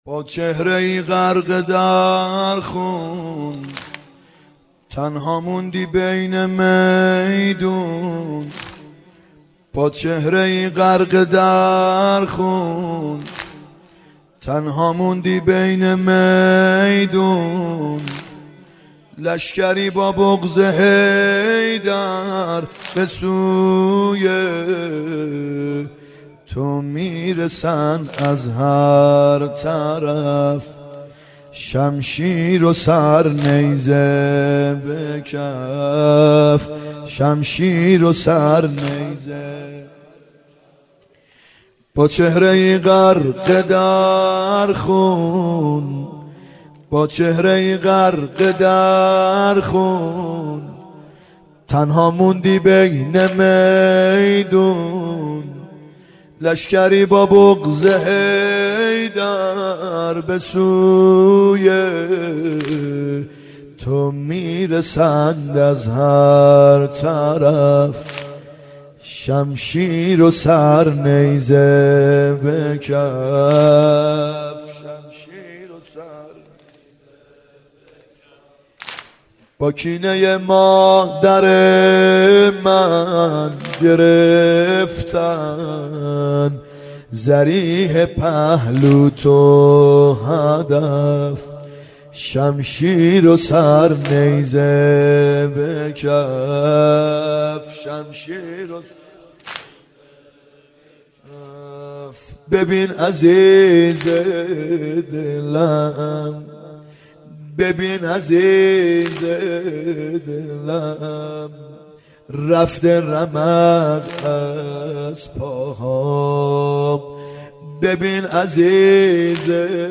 محرم 90 ( هیأت یامهدی عج)